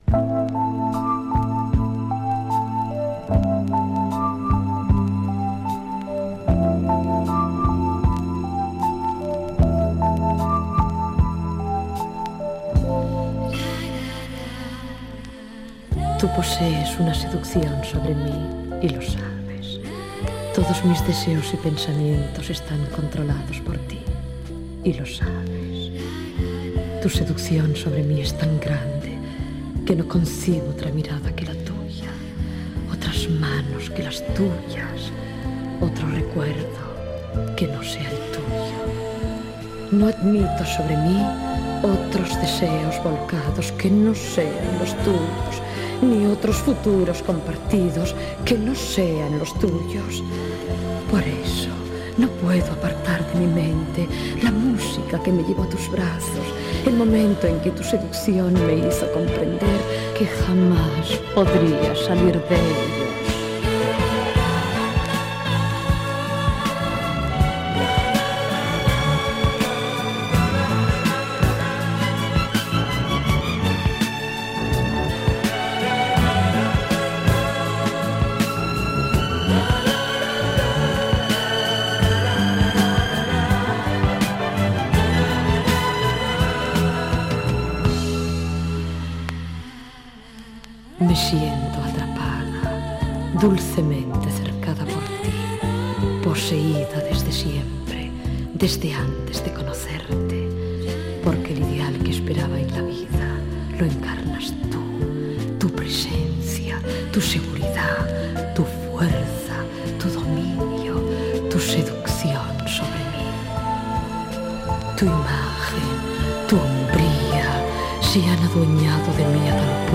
Enregistrament discogràfic del tema